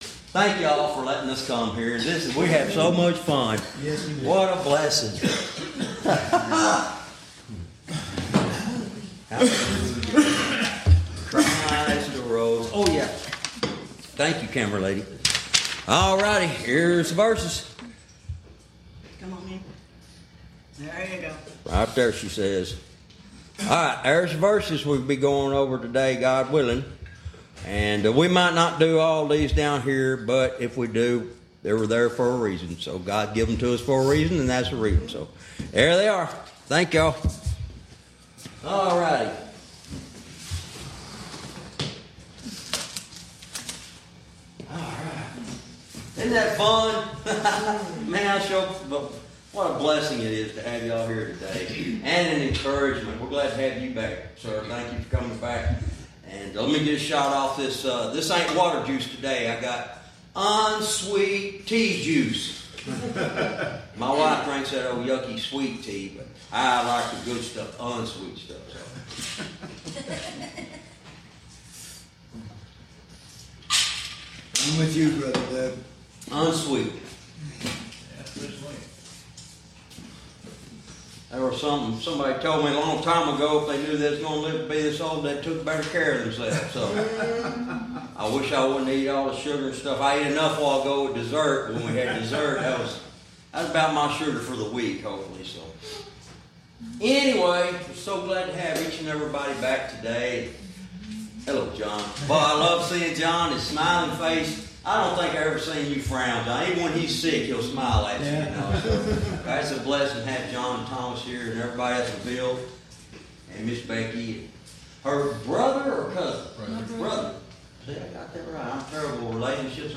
Verse by verse teaching - Lesson 52 verse 12